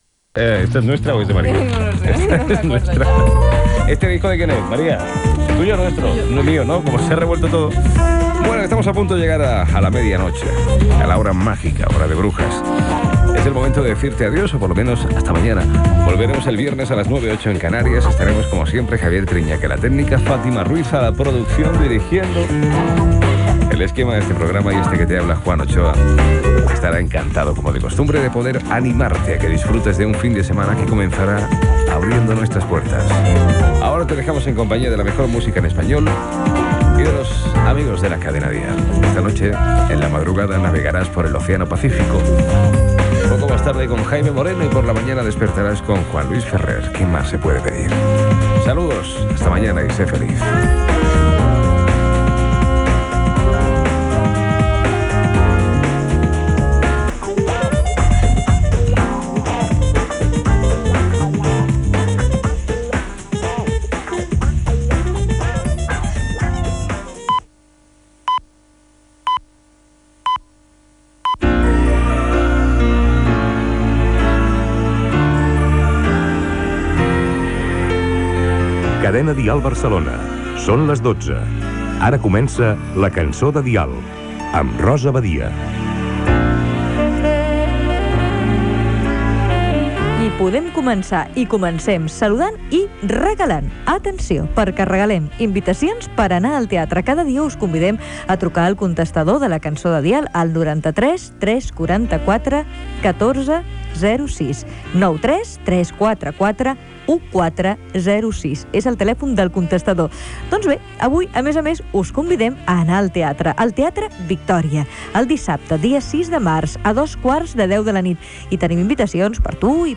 interpretació de Gossos, en directe, d'un tema musical i entrevista als integrants del grup Gènere radiofònic Musical